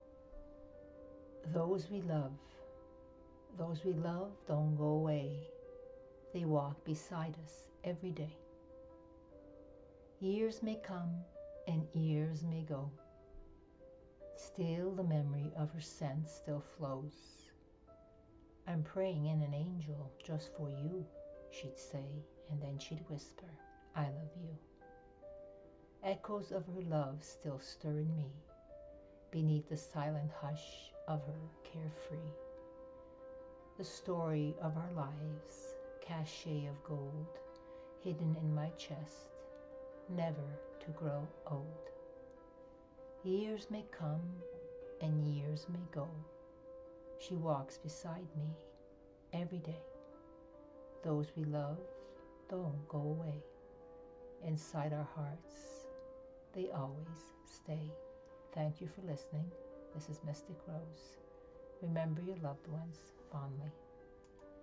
I just love how soothing your poetic voice is.